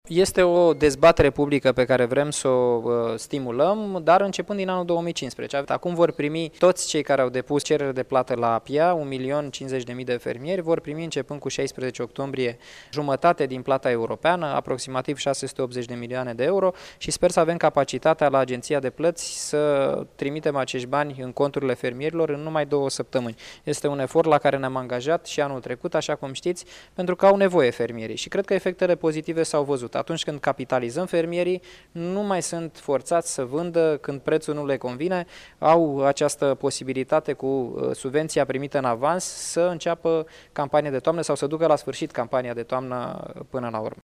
Deocamdată a fost iniţiată dezbaterea publică pe această temă, a anunţat, astăzi, la Iaşi, ministrul agriculturii Daniel Constantin: